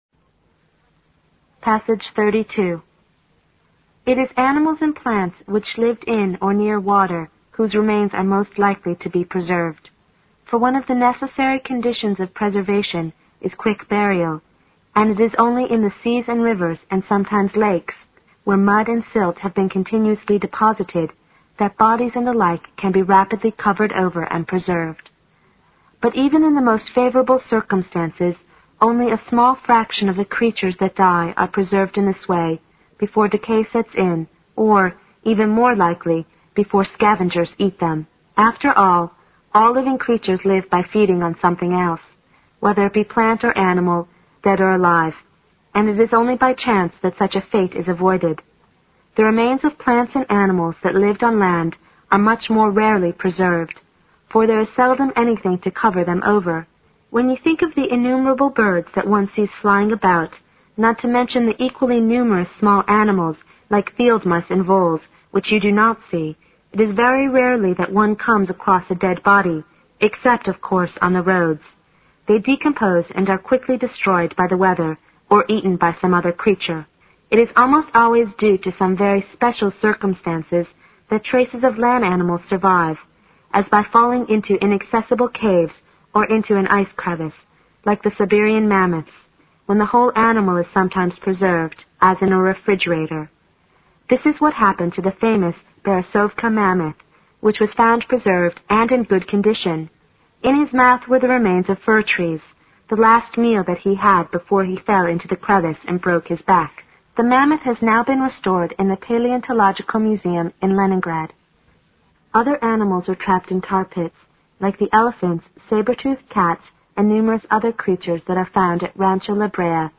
新概念英语85年上外美音版第四册 第32课 听力文件下载—在线英语听力室